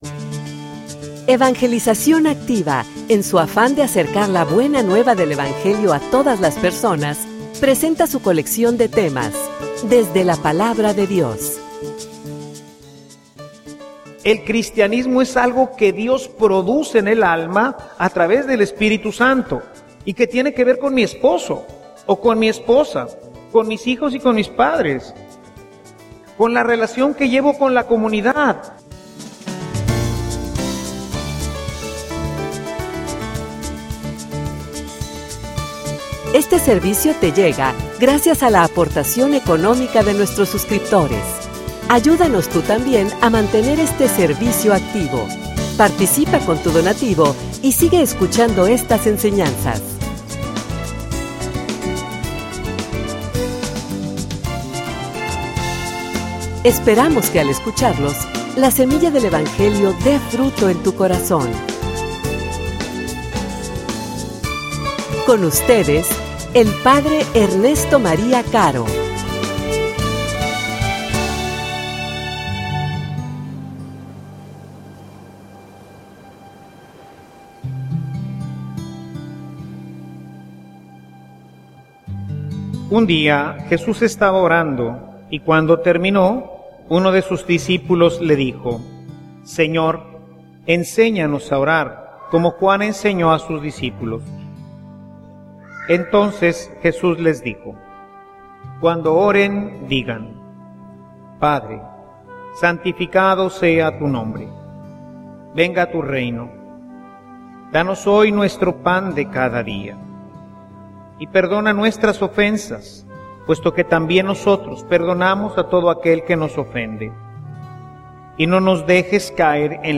homilia_Iglesia_ensename_a_orar.mp3